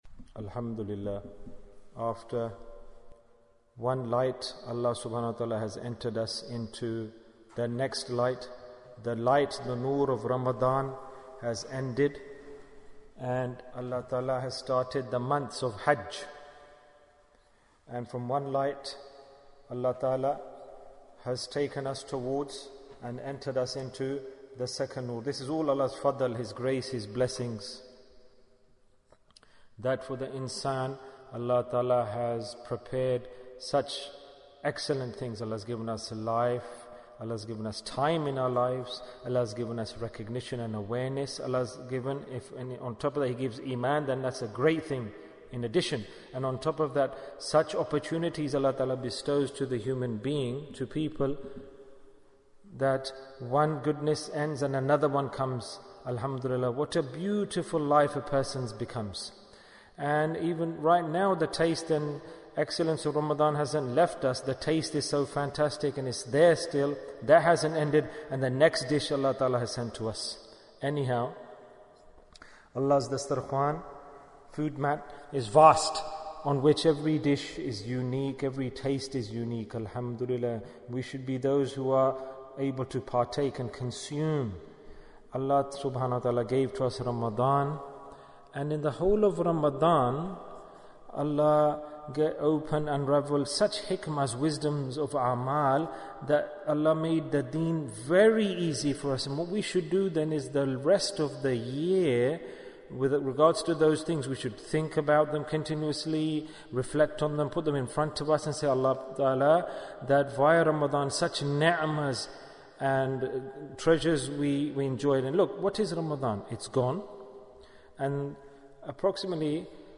Why Did We Enjoy Ramadhan? Bayan, 11 minutes13th May, 2021